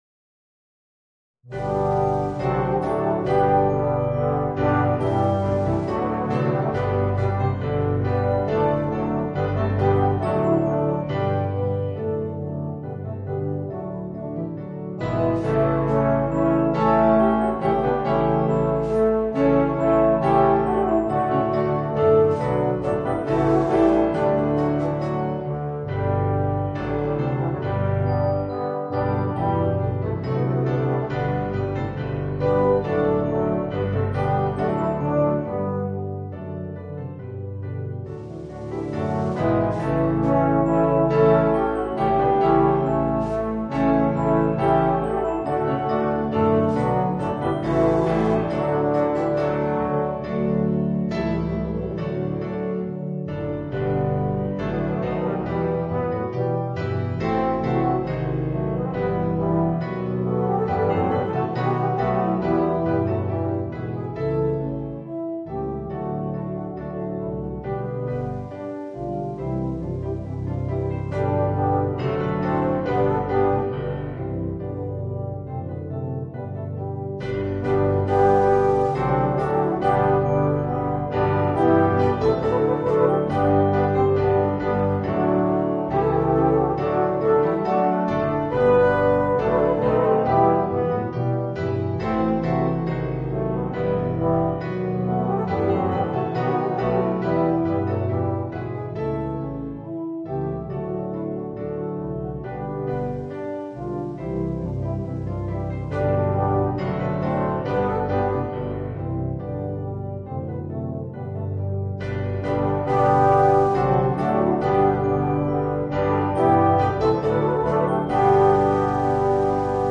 Voicing: 2 Baritones, 2 Euphoniums, 4 Tubas